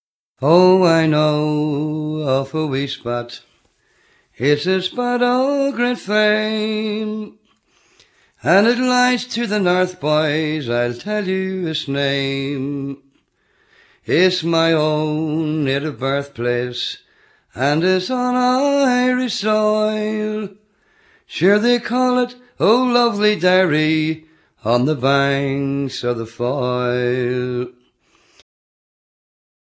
akordeon, uilleann pipes, flety, tin whistles, bodhran
gitara basowa
instrumenty perkusyjne i klawiszowe